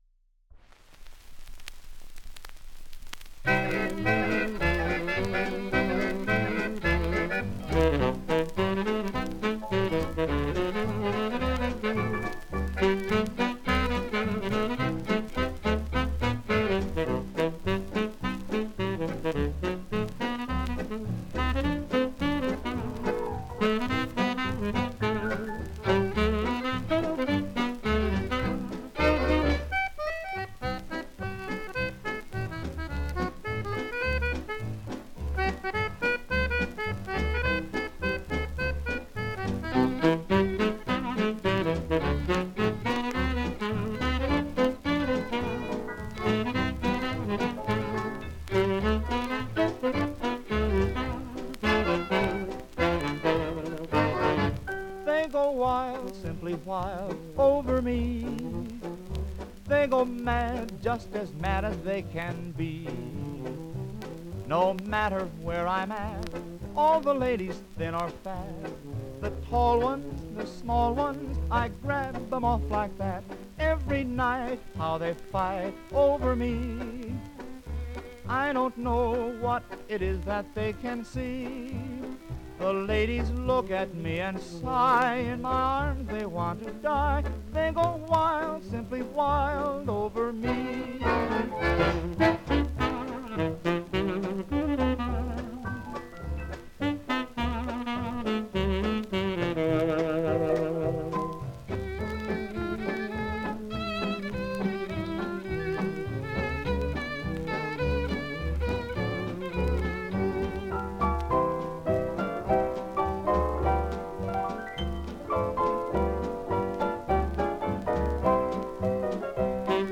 Genre: Medley.